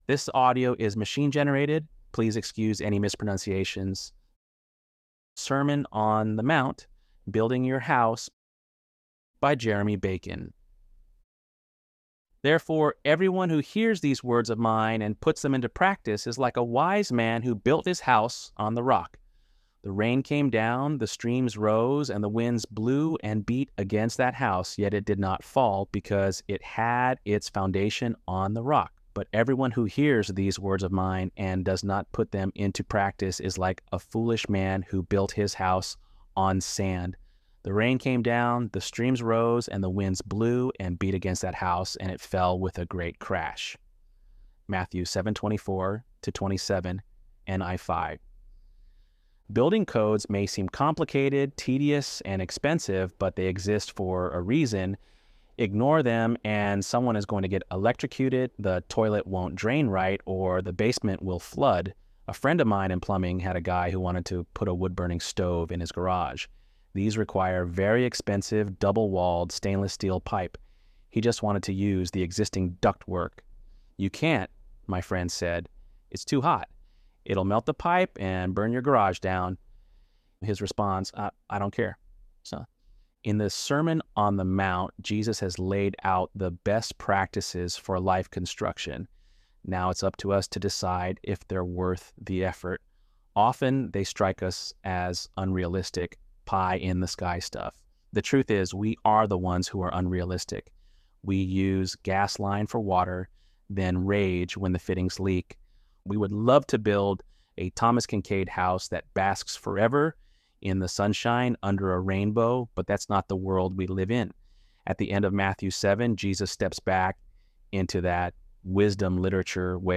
ElevenLabs_1_27.mp3